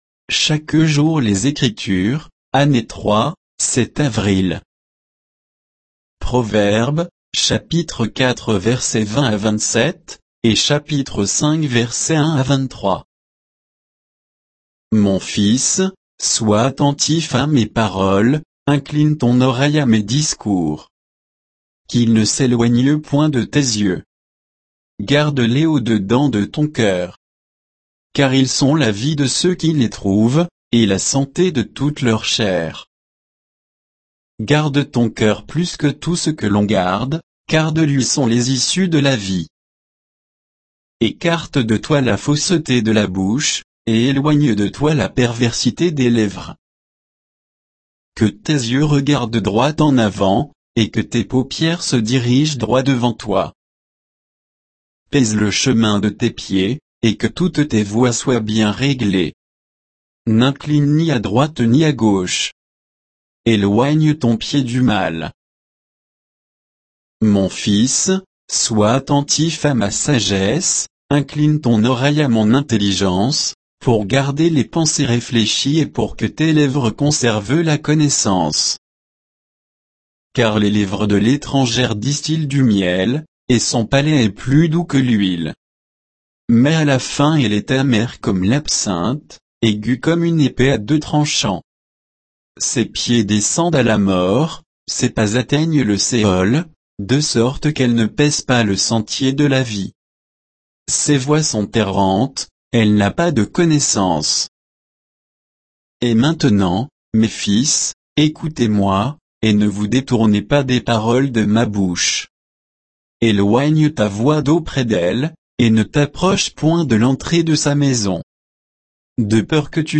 Méditation quoditienne de Chaque jour les Écritures sur Proverbes 4, 20 à 5, 23